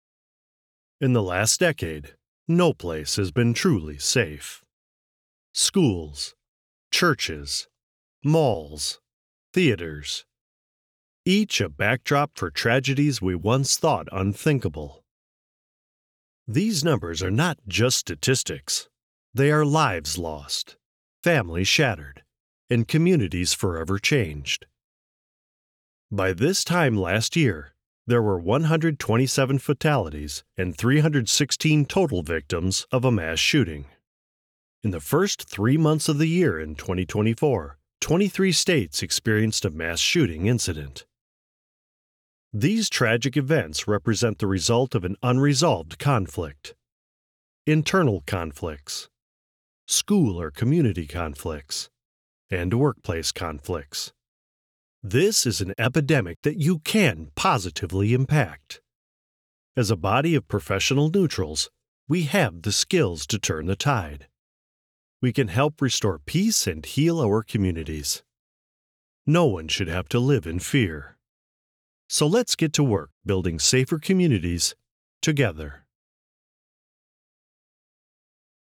American Voice Over Talent
Older Sound (50+)